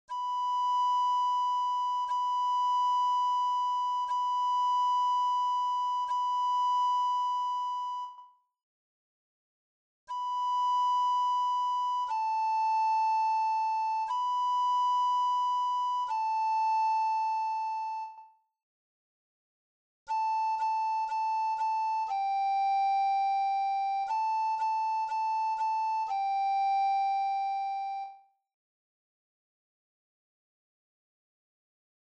Free printable introductory exercises for beginning soprano recorder.
intro-to-the-recorder-exercises.mp3